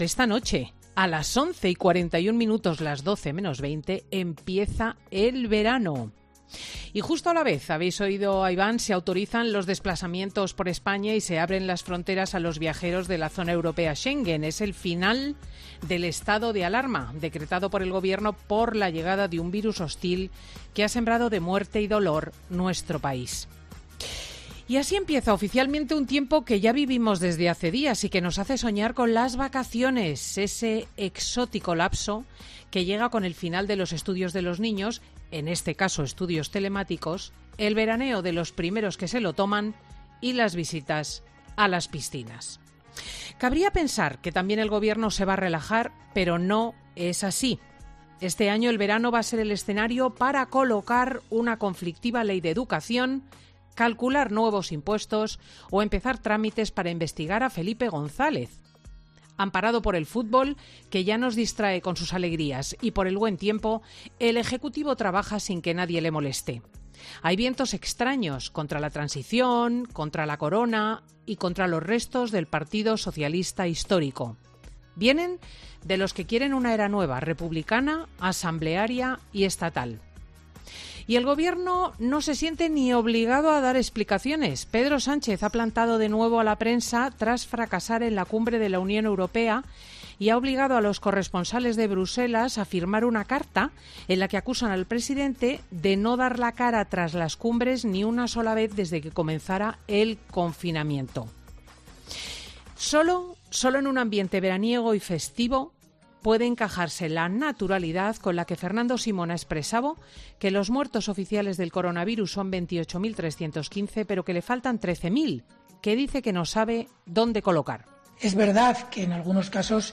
AUDIO: La presentadora de Fin de Semana analiza el cambio de postura de Podemos de apoyar una investigación en Congreso contra Felipe González por...
Monólogo de Cristina López Schlichting